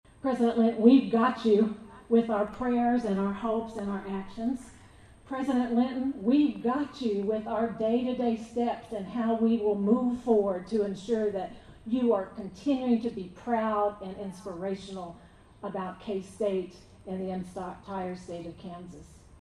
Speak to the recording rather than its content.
A community gathering was held outside Anderson Hall Tuesday evening as state, local and faith leaders came together in support of Kansas State University President Richard Linton, who earlier in the day announced his diagnosis of throat and tongue cancer.